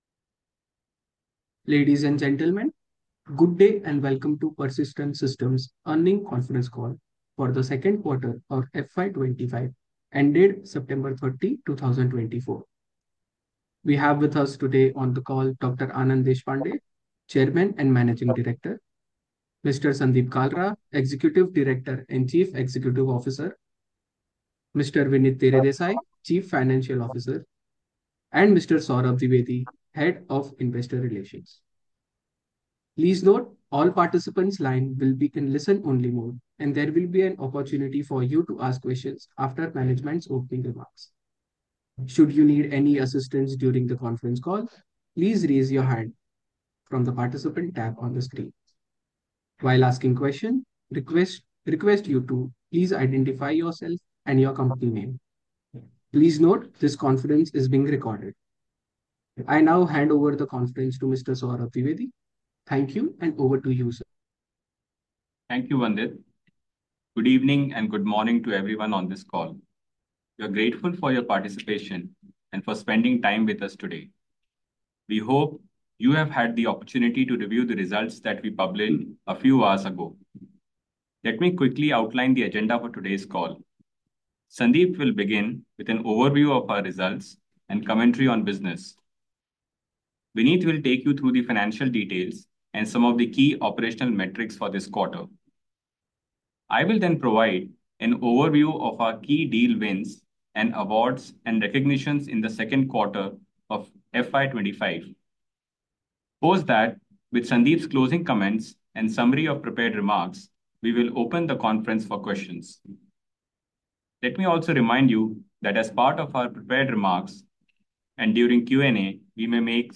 Concalls
Analyst-Call-Audio-Recording-Q2FY25.mp3